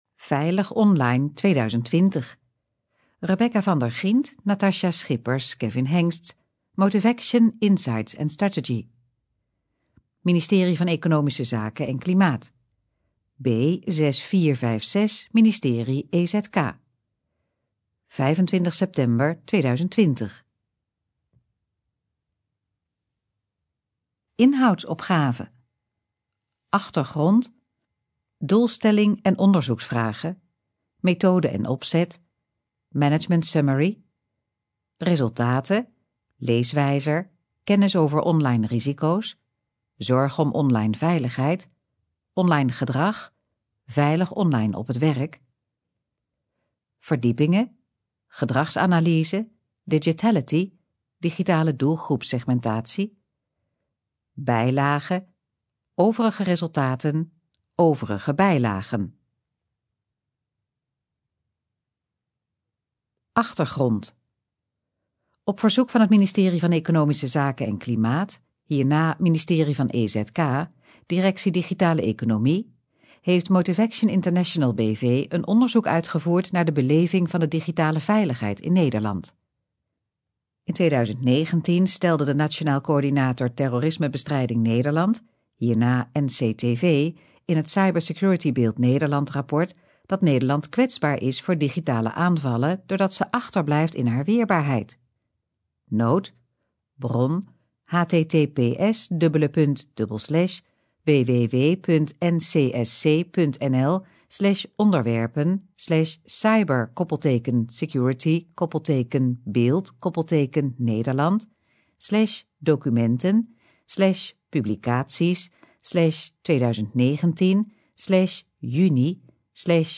Gesproken versie van het rapport Veilig Online 2020